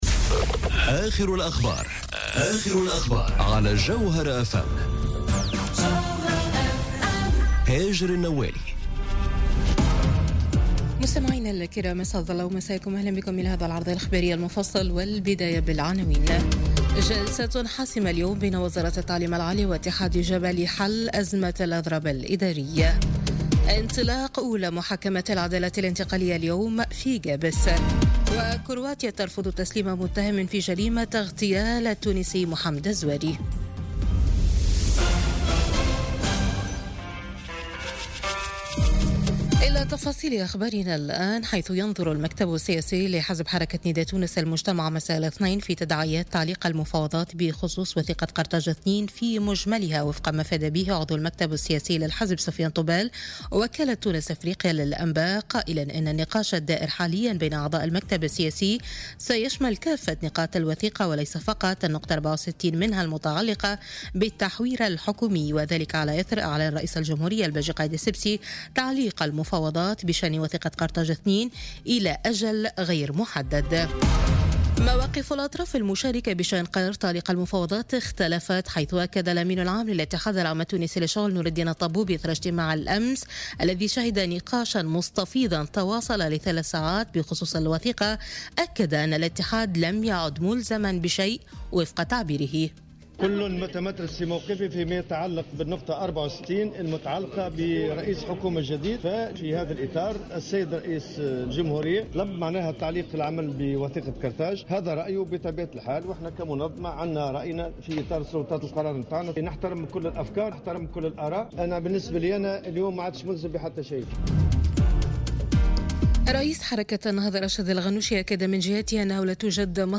نشرة أخبار منتصف الليل ليوم الثلاثاء 29 ماي 2018